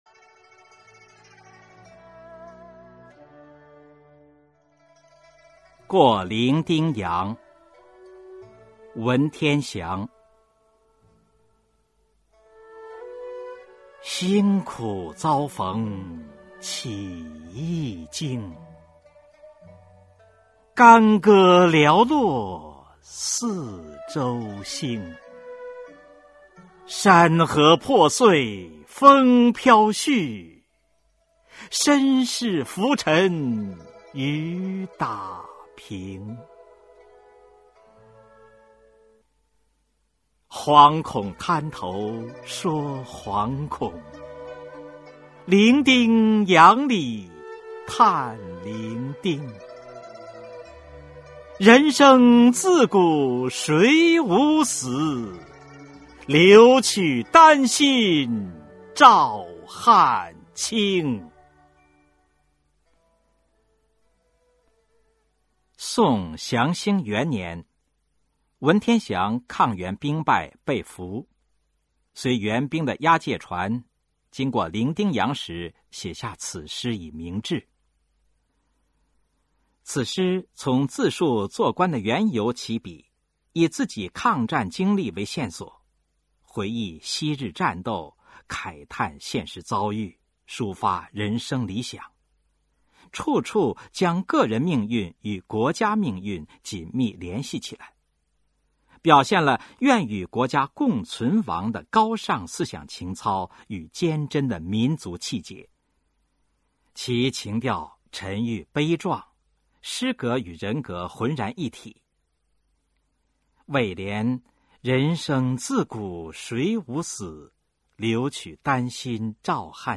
文天祥《过零丁洋》原文和译文（含赏析、朗读）